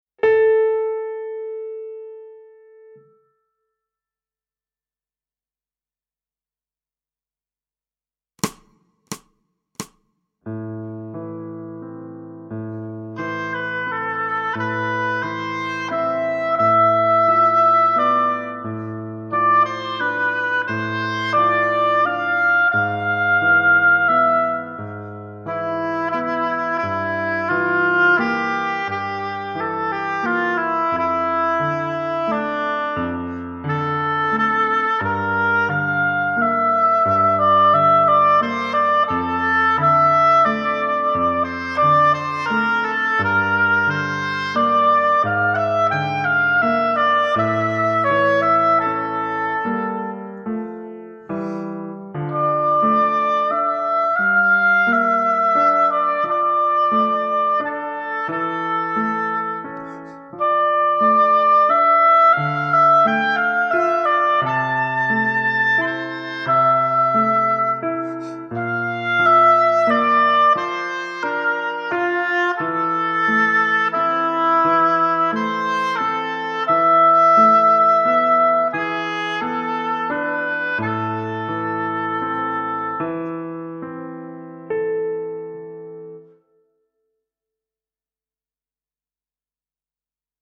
Pour hautbois et piano